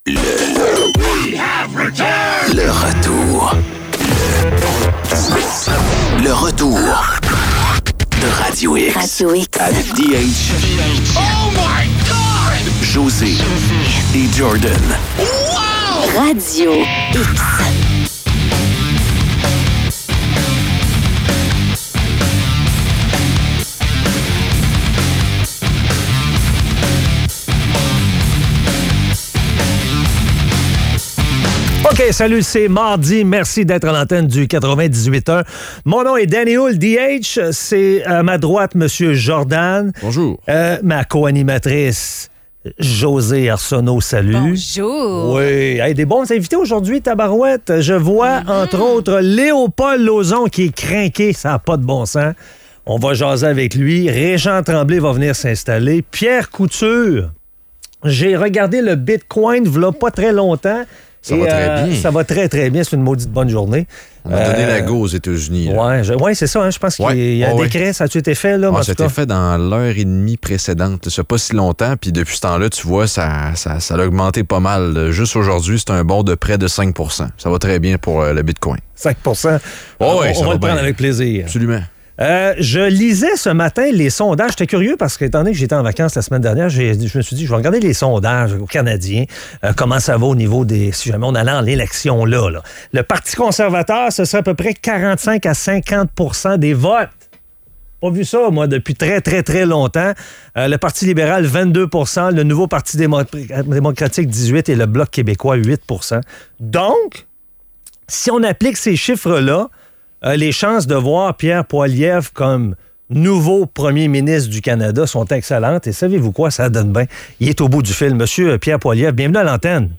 Entrevue avec Pierre Poilievre, chef du parti Conservateur du Canada.